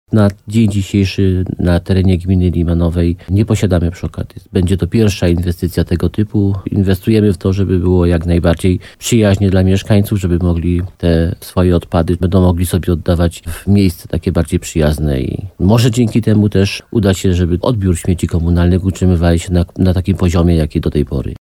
– liczy wójt gminy Limanowa, Jan Skrzekut.